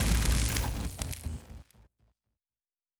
pgs/Assets/Audio/Sci-Fi Sounds/Mechanical/Engine 3 Stop.wav at master
Engine 3 Stop.wav